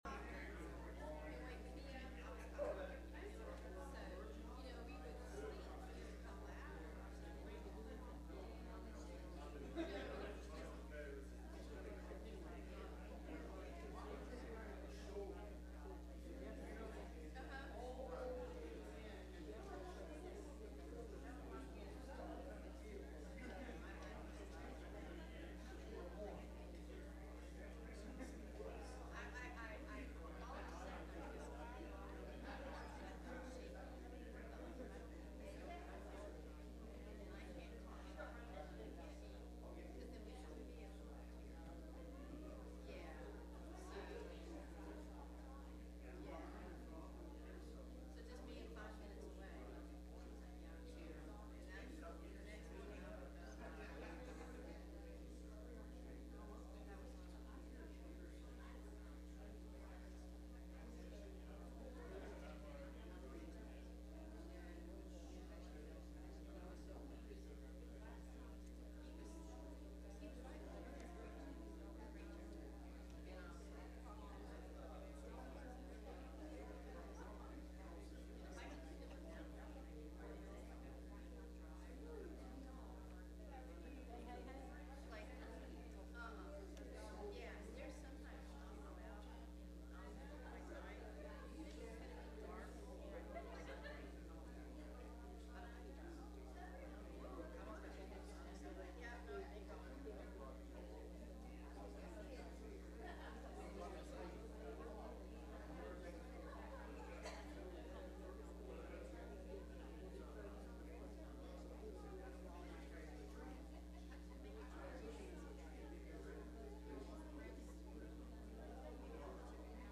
Youth Service
Service Type: Sunday Evening